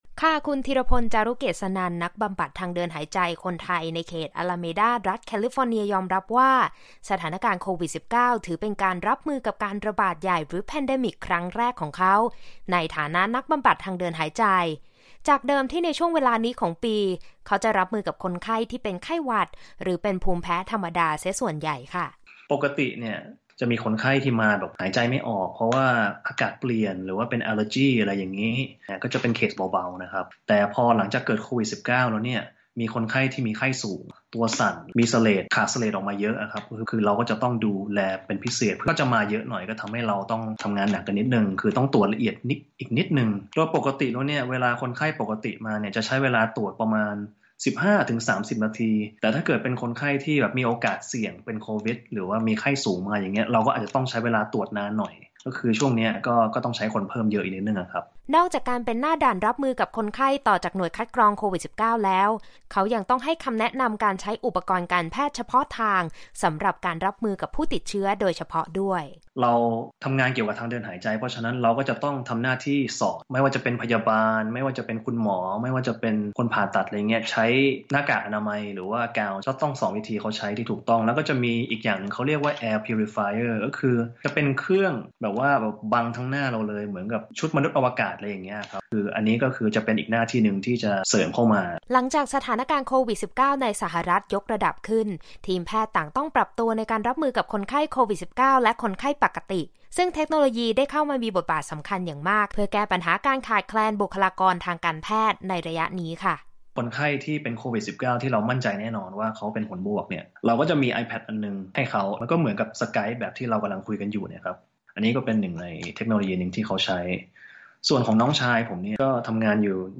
คุยกับ “นักบำบัดทางเดินหายใจ” คนไทย ด่านหน้ารับมือโควิด-19 ในแคลิฟอร์เนีย